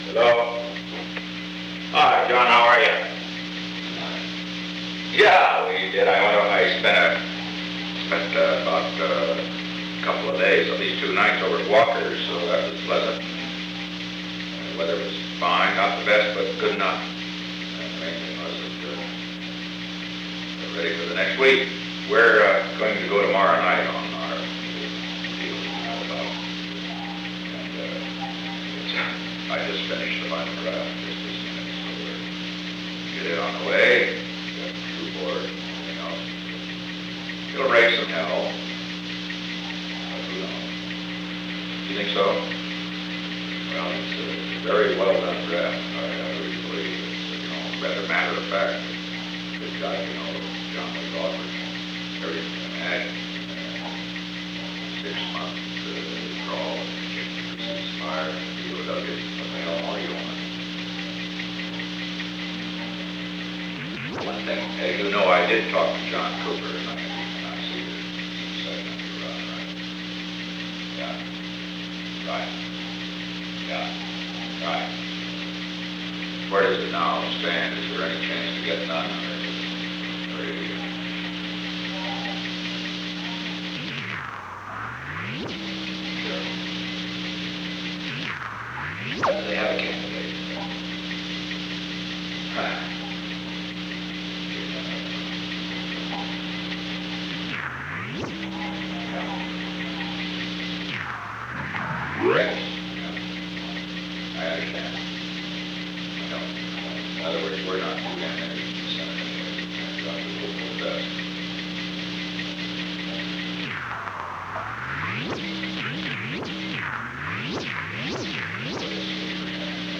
On January 24, 1972, President Richard M. Nixon and John N. Mitchell met in the President's office in the Old Executive Office Building from 4:27 pm to 4:55 pm. The Old Executive Office Building taping system captured this recording, which is known as Conversation 317-017 of the White House Tapes. Nixon Library Finding Aid: Conversation No. 317-017 Date: January 24, 1972 Time: 4:27 pm - 4:55 pm Location: Executive Office Building The President talked with John N. Mitchell.